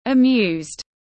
Amused /ə’mju:zd/
Bạn cũng có thể đọc theo phiên âm của từ amused /ə’mju:zd/ kết hợp với nghe phát âm sẽ đọc chuẩn hơn.